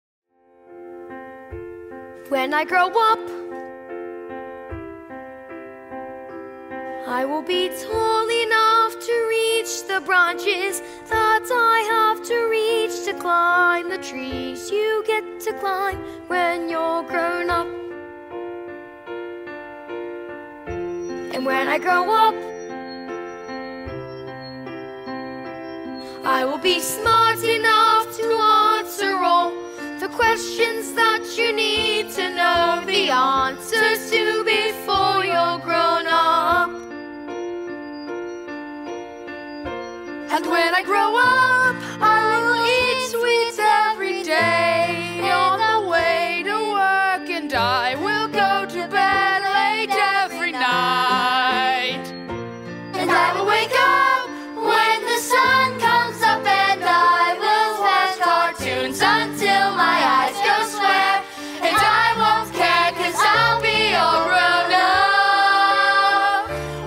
Listen to: (Vocal Support)